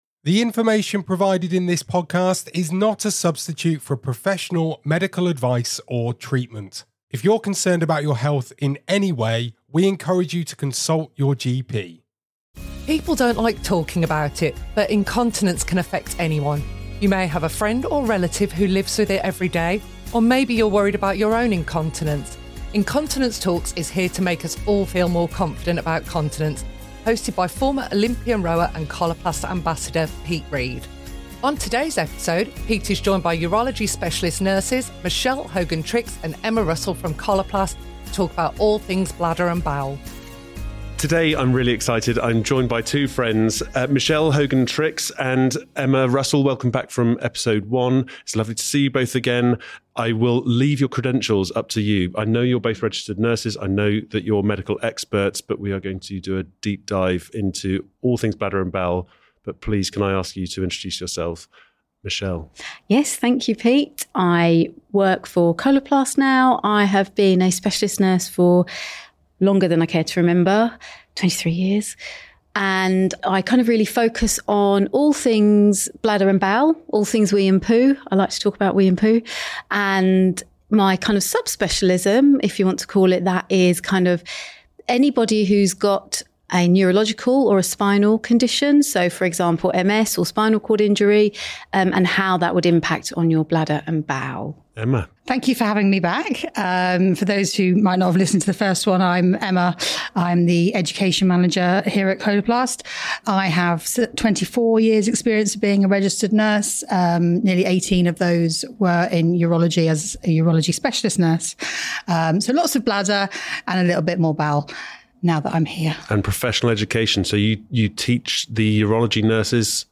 Retired British Olympic rower, Pete Reed is joined by urology specialist nurses